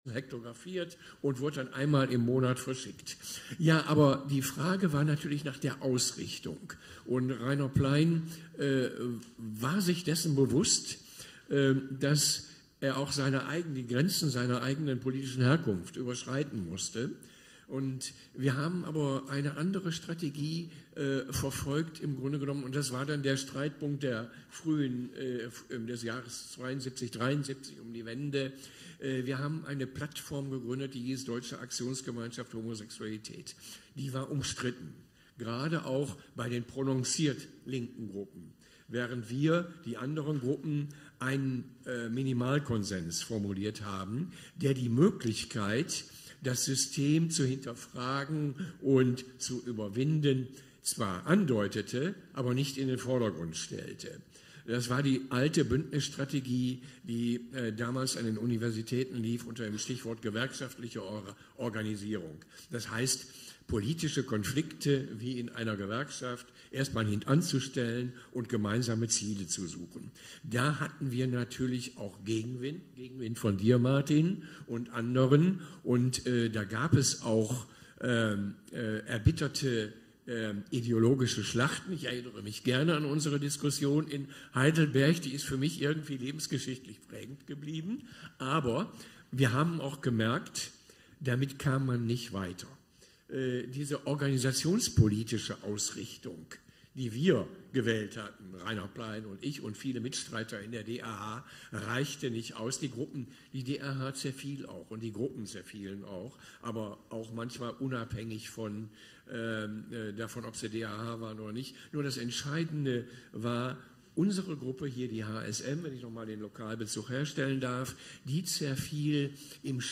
Interviewausschnitt vom Festakt 2022